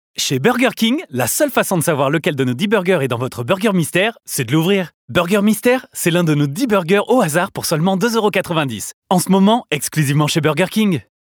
Voix Pub - Burger King